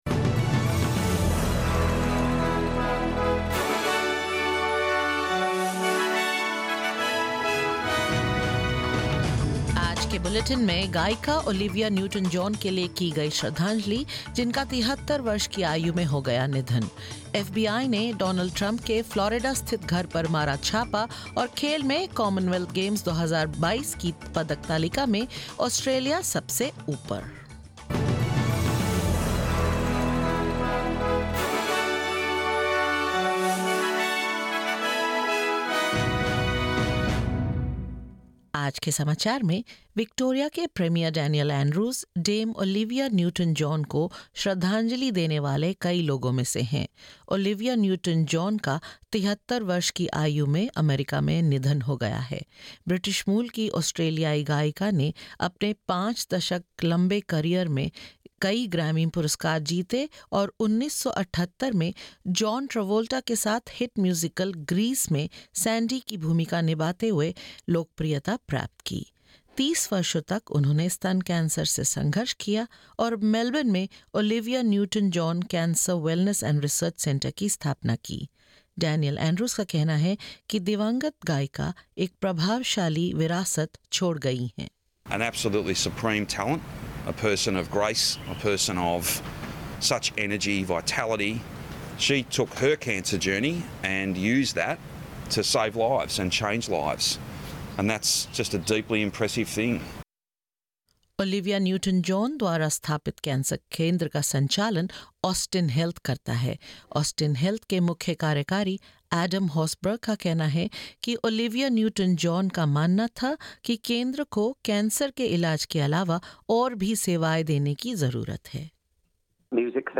In this latest SBS Hindi bulletin: Tributes flow for singer Olivia Newton-John who died aged 73, Donald Trump condemns an FBI raid on his Florida home; Australia secures top spot on the 2022 Commonwealth Games medal tally and more.